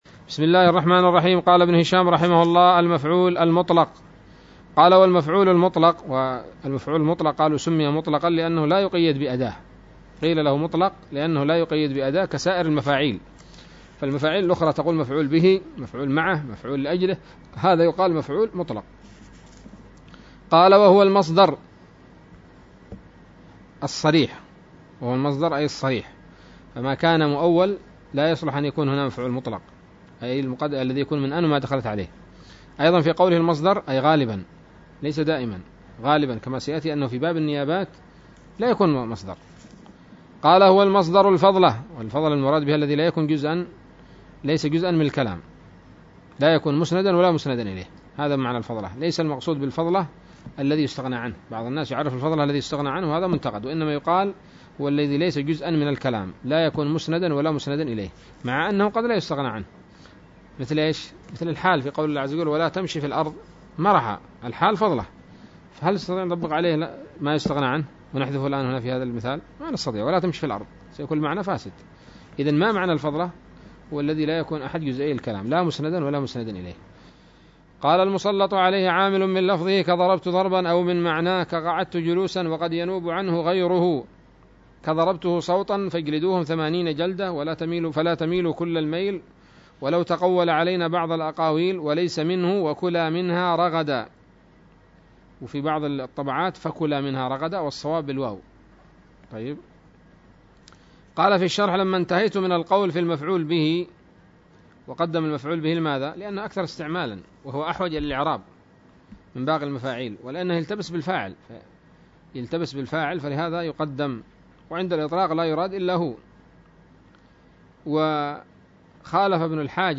الدرس الثالث والتسعون من شرح قطر الندى وبل الصدى